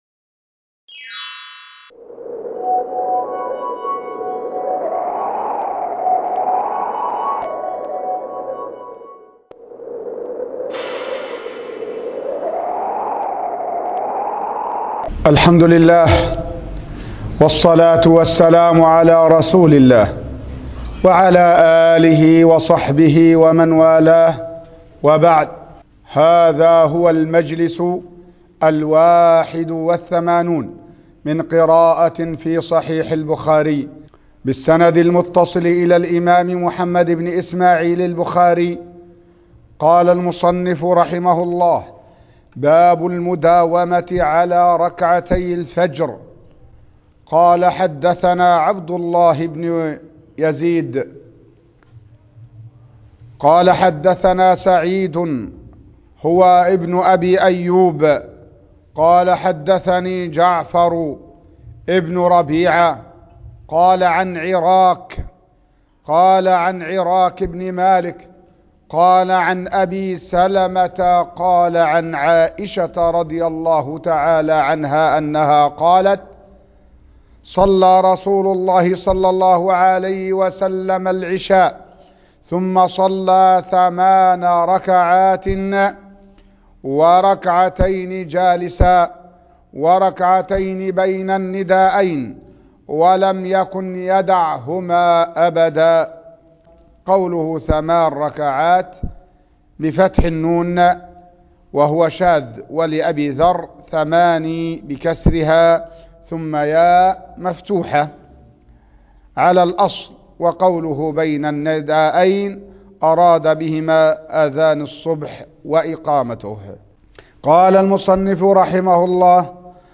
الدرس ( 81) قراءة صحيح البخاري